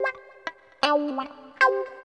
74 GTR 3  -L.wav